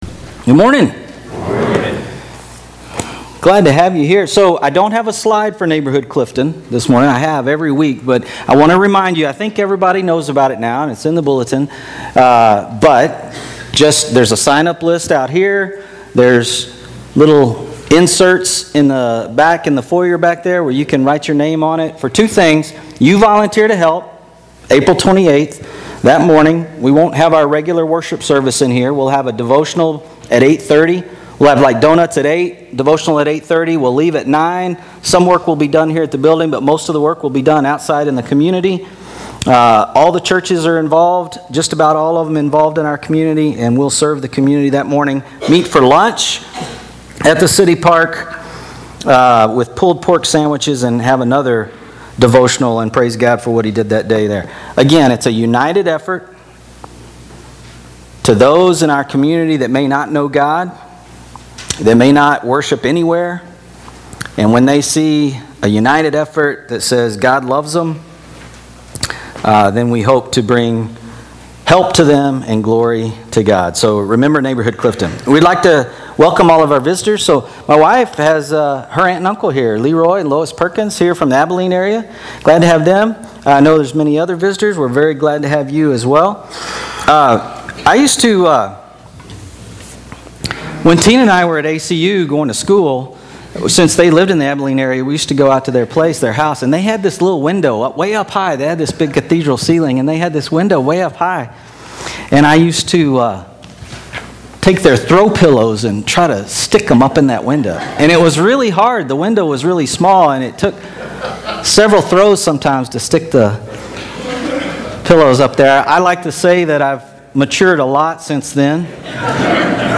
Sermons - Clifton Church of Christ